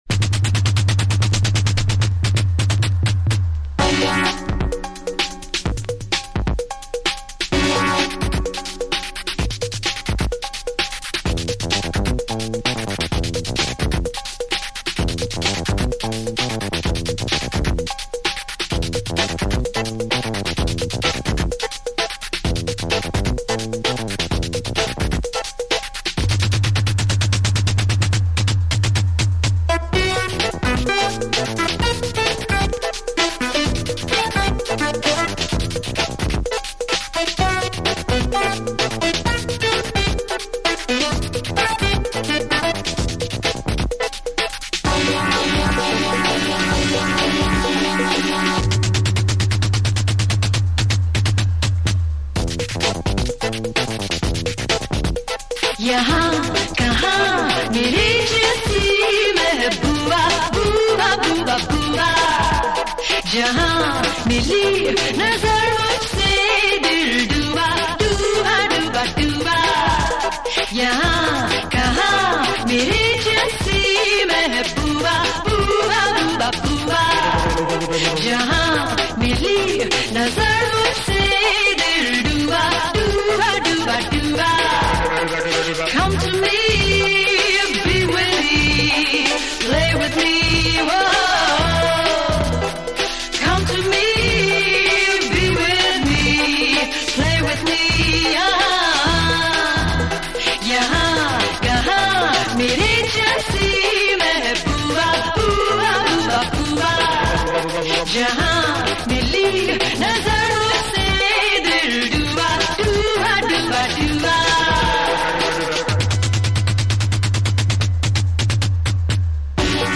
Always a big demand on this Bollywood soundtrack !
Killer Indian electro funk.